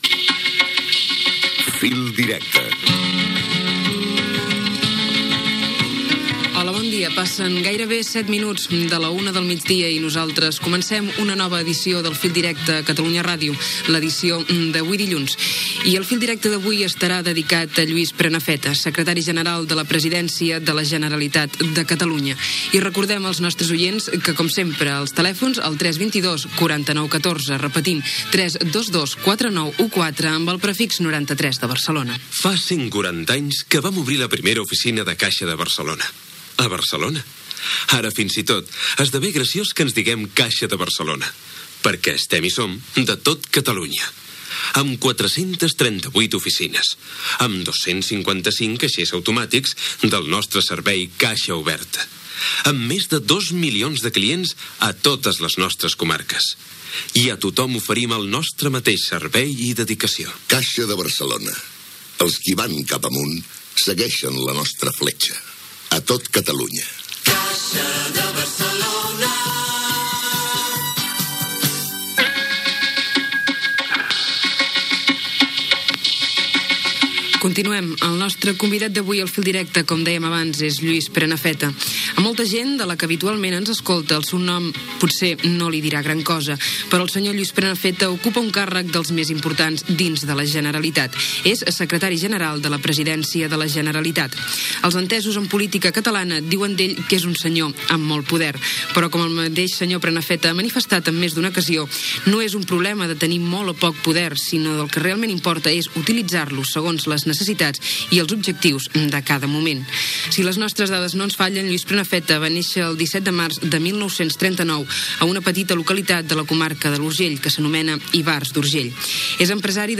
Careta del programa, presentació, telèfon, publicitat, entrevista a Lluís Prenafeta, secretari general de la Presidència de la Generalitat.
Informatiu